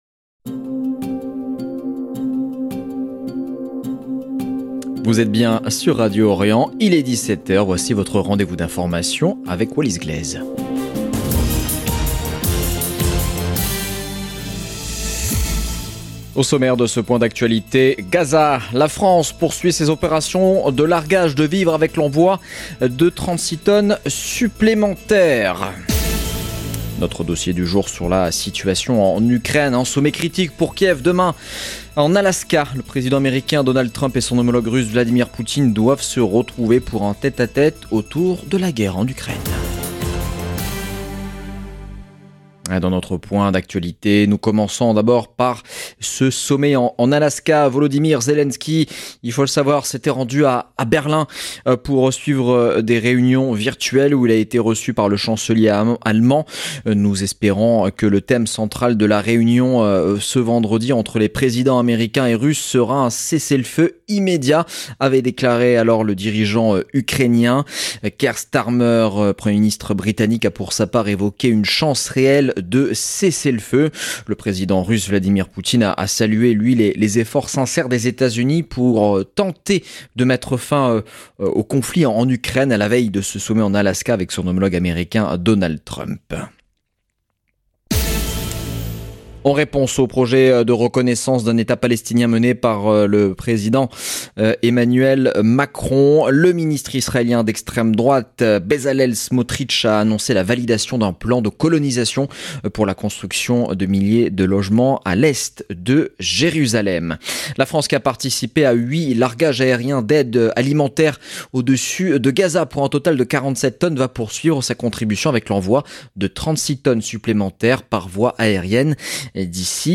Magazine d'information du jeudi 14 août 2025